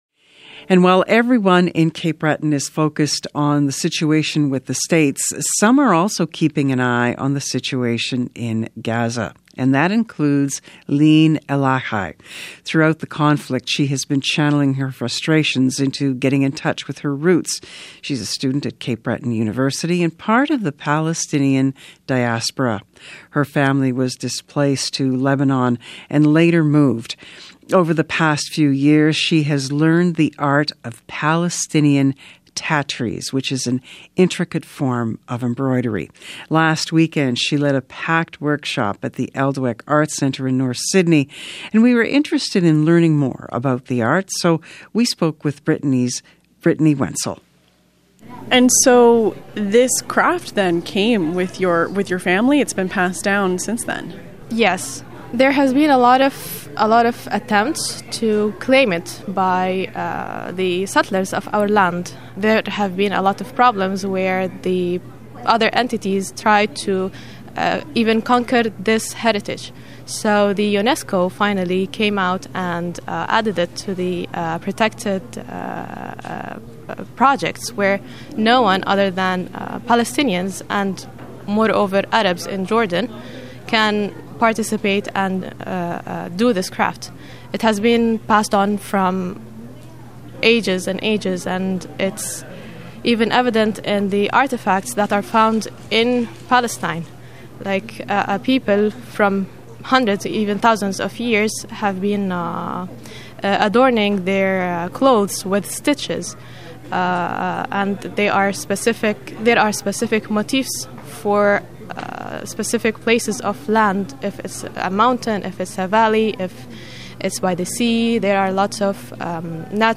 Thank you to CBC Radio’s Information Morning Cape Breton for sharing the audio segment!